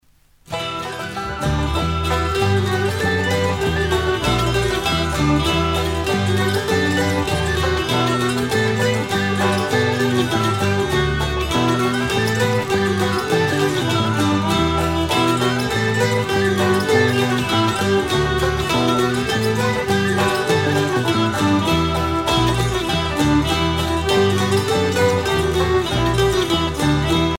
Groupe celtique
Pièce musicale éditée